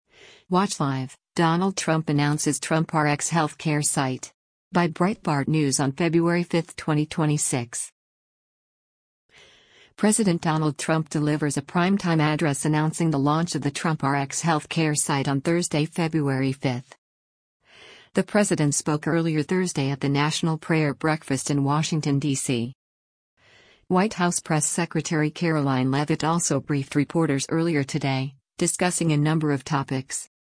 President Donald Trump delivers a primetime address announcing the launch of the Trump RX health care site on Thursday, February 5.